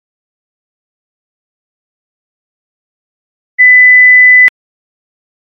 (Pour écouter les sons à différentes fréquences ci-dessous, il suffit de cliquer sur celui que vous souhaitez entendre)
2 kHz] [